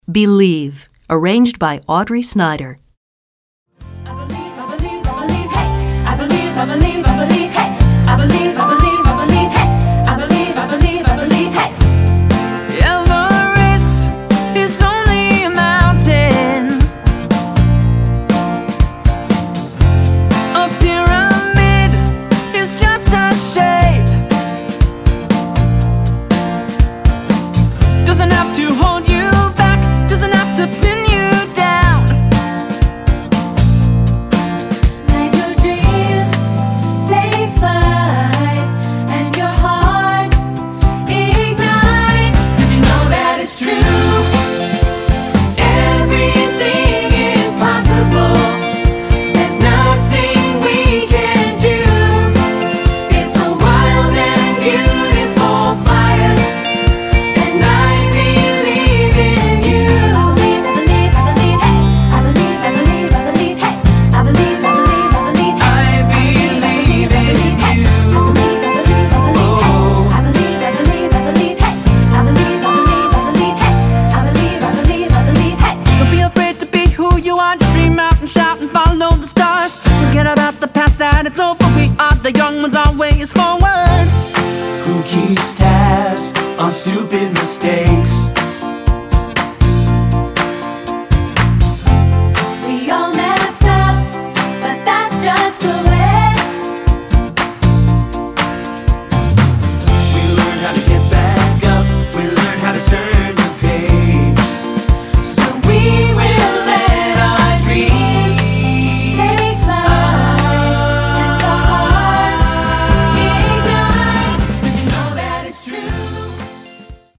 Choral Early 2000's Pop Movie/TV/Broadway
3 Part Mix